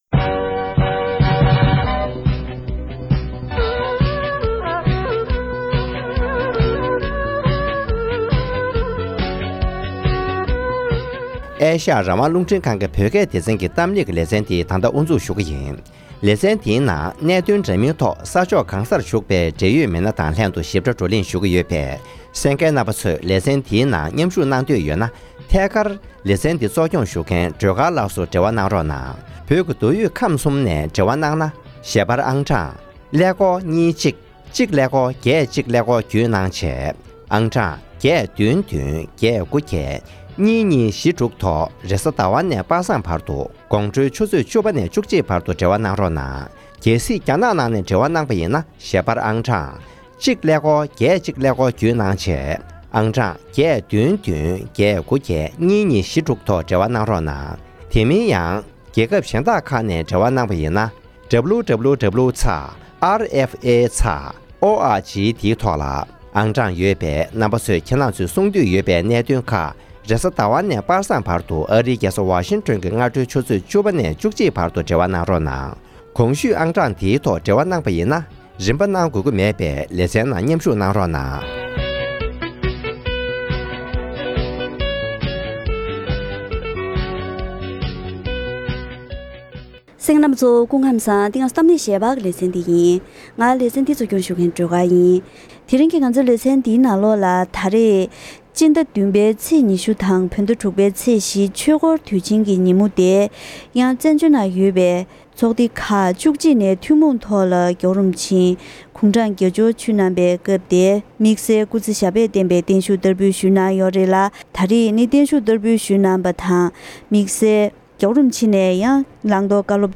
༄༅། །ཐེངས་འདིའི་གཏམ་གླེང་ཞལ་པར་ལེ་ཚན་ནང་སྤྱི་ནོར་༸གོང་ས་༸སྐྱབས་མགོན་ཆེན་པོ་མཆོག་ལ་བཙན་བྱོལ་ནང་ཡོད་པའི་ཚོགས་སྡེ་ཁག་༡༡ནས་༧གོང་ས་མཆོག་སྐུ་ཚེ་ཞབས་པད་བརྟན་པའི་བརྟན་བཞུགས་བསྟར་འབུལ་ཞུས་པ་དང་༸གོང་ས་མཆོག་ནས་བཀའ་སློབ་གནང་བ་ཁག་ངོ་སྤྲོད་དང་བཅས་འབྲེལ་ཡོད་མི་སྣ་ཁག་ཅིག་དང་ལྷན་བཀའ་མོལ་ཞུས་པ་ཞིག་གསན་རོགས་གནང་།